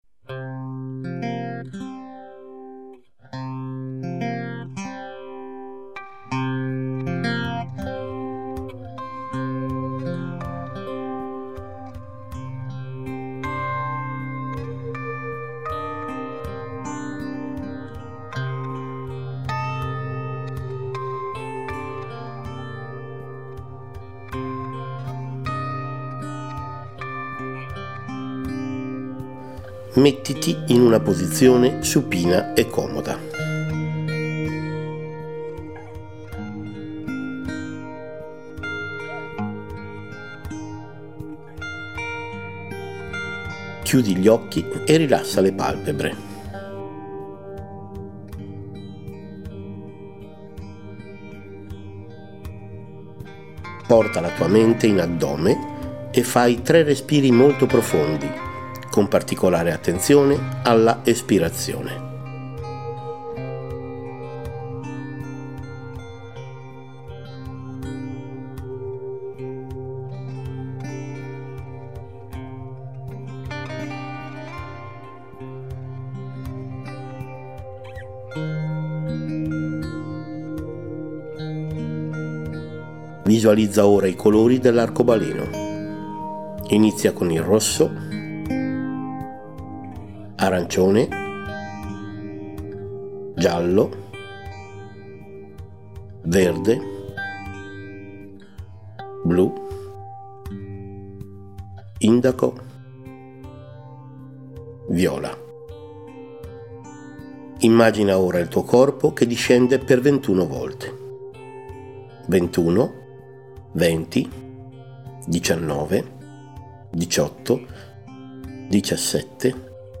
Ascolta la traccia dell’INVENTARIO per praticare l’esercizio Sincrony per sviluppare la propriocezione con una guida audio, utile almeno per le prime volte: